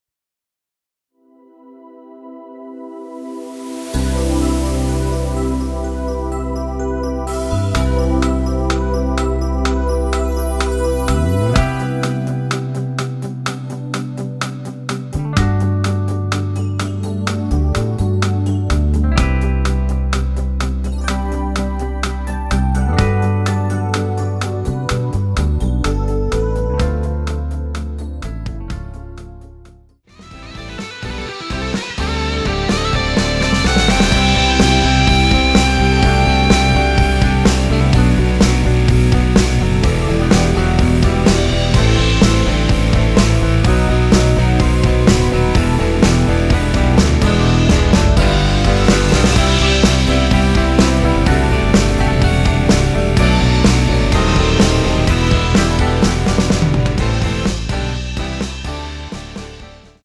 원키에서(-2)내린 MR입니다.
앞부분30초, 뒷부분30초씩 편집해서 올려 드리고 있습니다.
중간에 음이 끈어지고 다시 나오는 이유는